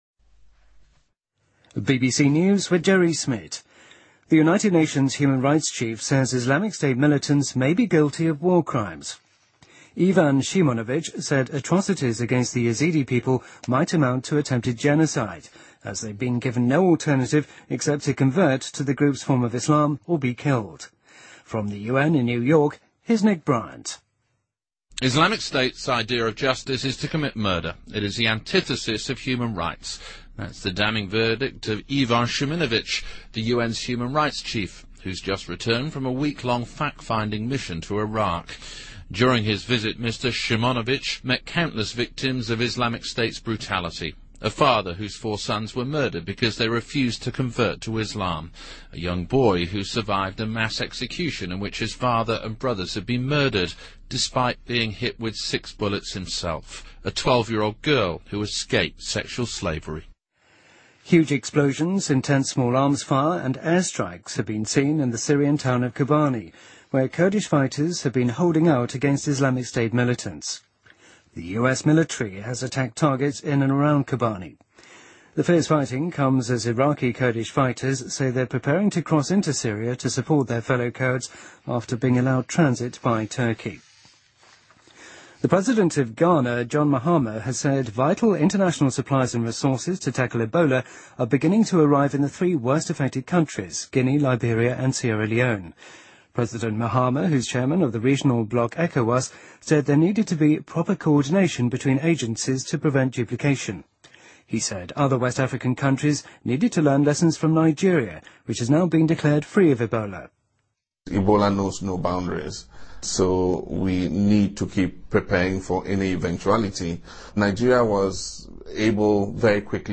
BBC news,美国气候官员表示上个月全球平均气温是自记录以来最高气温